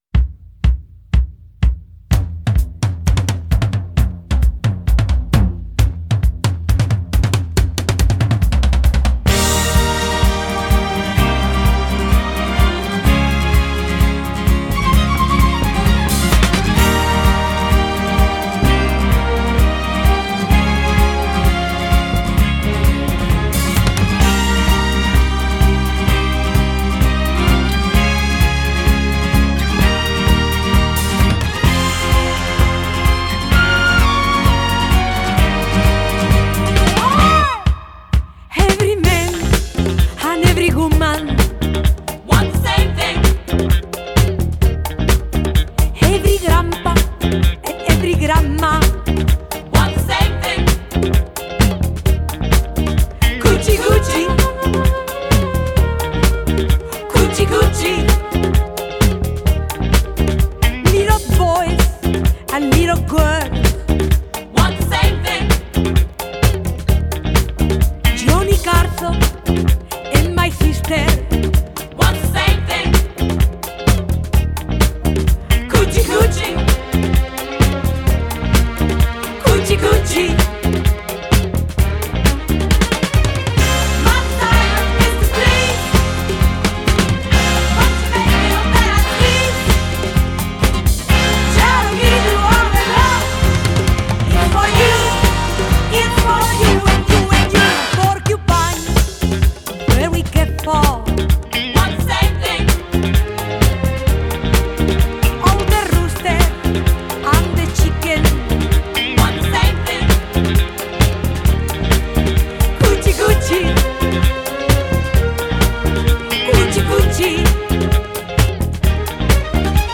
Genre: Funk / Soul, Disco